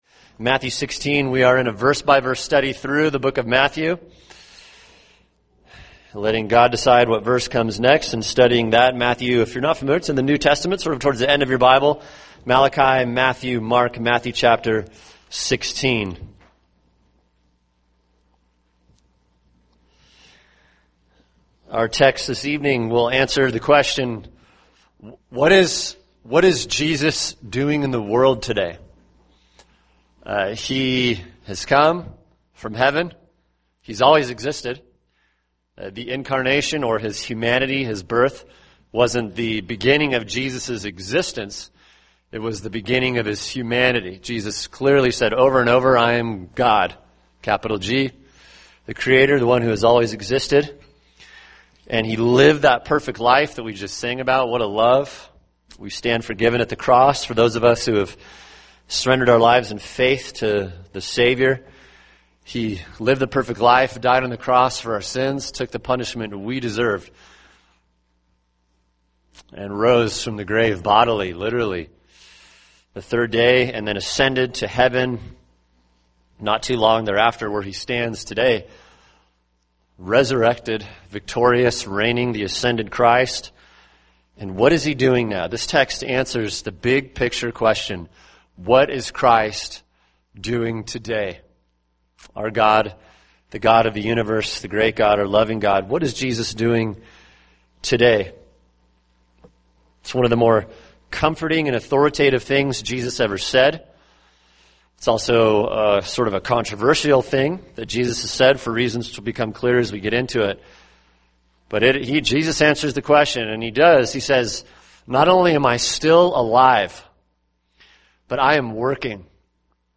[sermon] Matthew 16:18-20 – Christ and His Building Project | Cornerstone Church - Jackson Hole